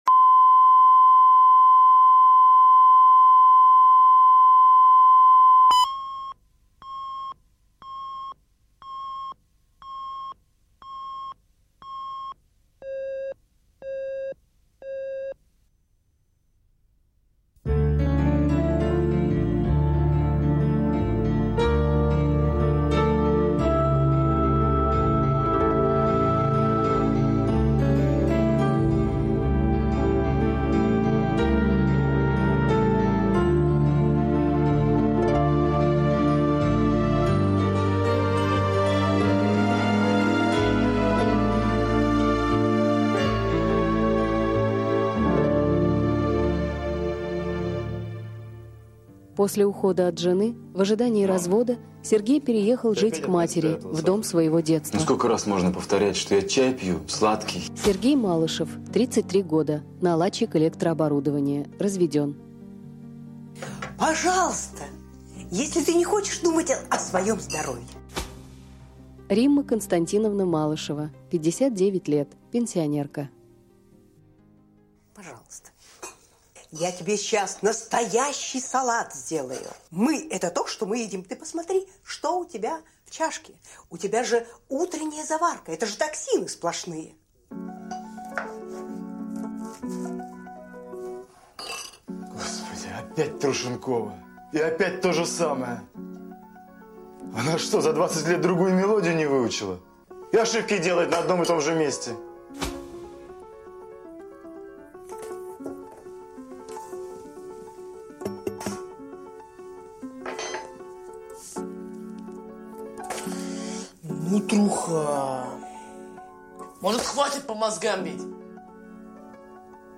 Аудиокнига Почти забытая мелодия | Библиотека аудиокниг
Прослушать и бесплатно скачать фрагмент аудиокниги